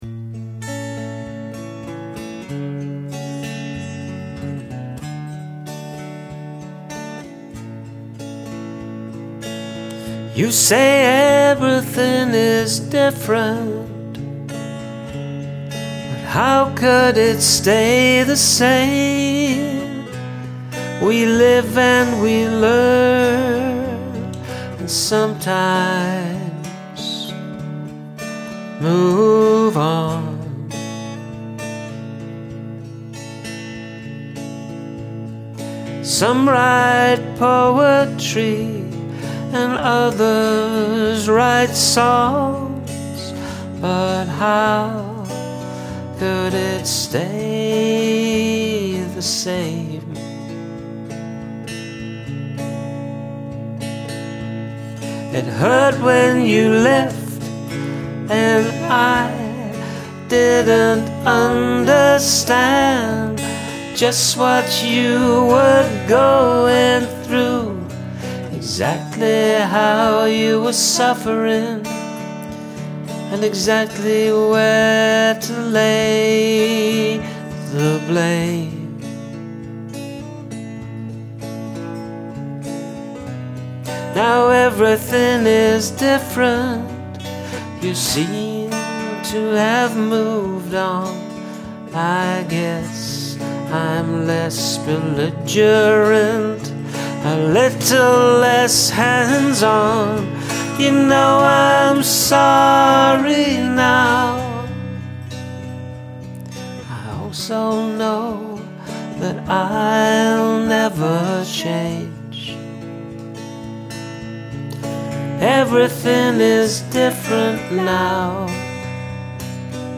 Some nice vocal harmonies too
Fantastic song, singing, playing and atmosphere.